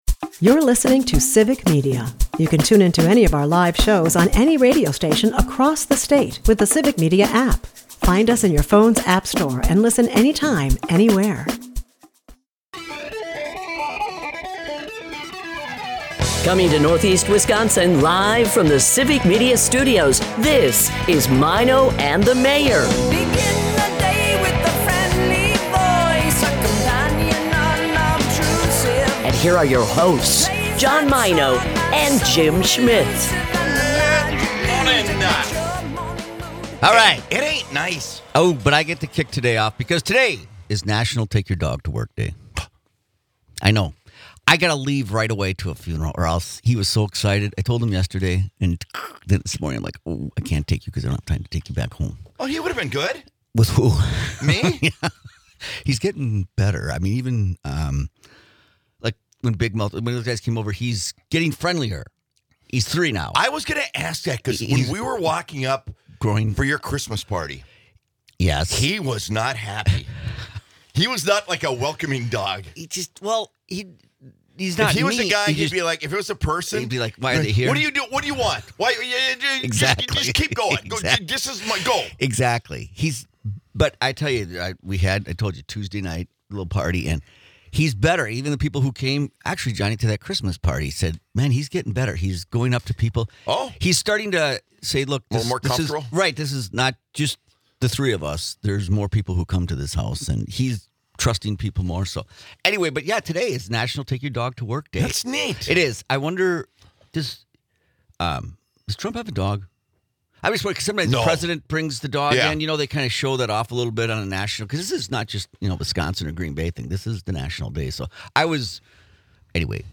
Broadcasting from the Civic Media Studios in Northeast Wisconsin